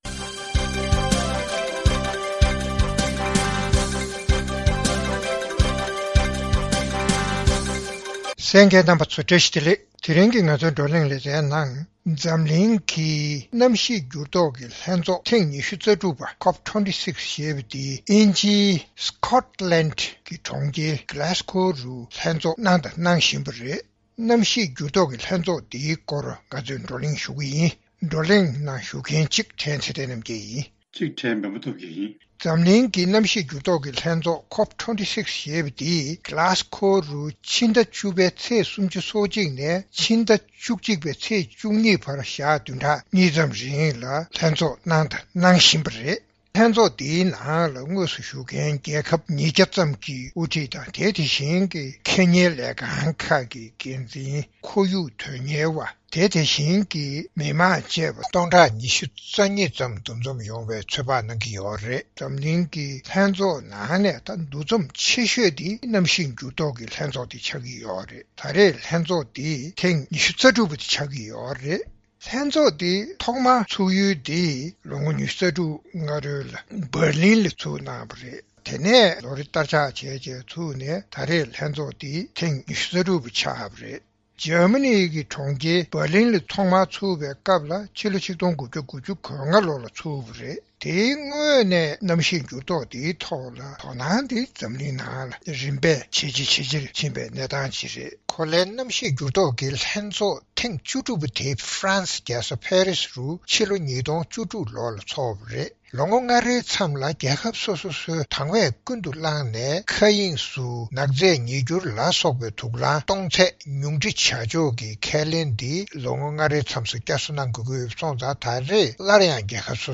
རྩོམ་སྒྲིག་པའི་གླེང་སྟེགས་ཞེས་པའི་ལེ་ཚན་ནང་། མཉམ་སྦྲེལ་རྒྱལ་ཚོགས་ཀྱིས་གནམ་གཤིས་འགྱུར་ལྡོག་ལ་གདོང་ལེན་བྱེད་ཕྱོགས་ཀྱི་ཚོགས་འདུ་ COP26ཞེས་པ་དབྱིན་ཇིའི་མངའ་ཁོངས་Scotland ཡི་གྲོང་ཁྱེར་Glasgow རུ་སྐོང་འཚོག་གནང་བཞིན་པའི་སྐོར་བགྲོ་གླེང་གནང་བ་གསན་རོགས་གནང་།